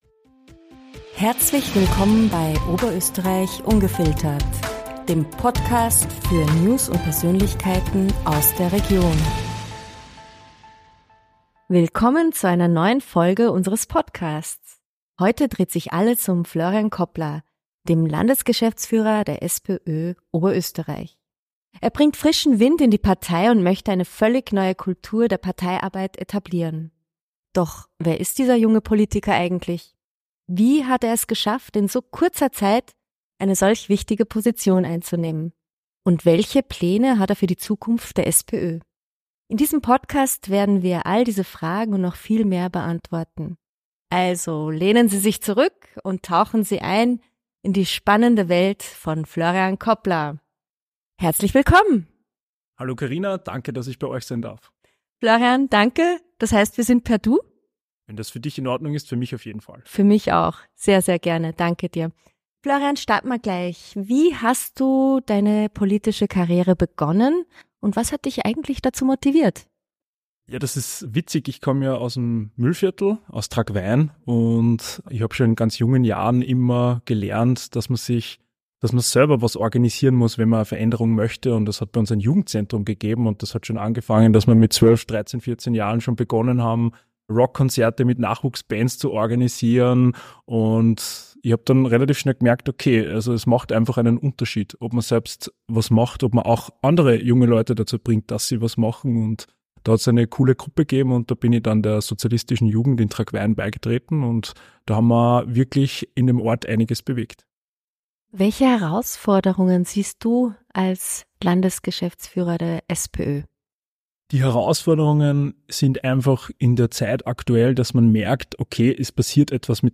Landesgeschäftsführer der SPÖ OÖ Florian Koppler im Podcast-Talk ~ OÖ ungefiltert Podcast
Heute haben wir Florian Koppler, Landesgeschäftsführer der SPÖ Oberösterreich und Gemeinderat, zu Gast.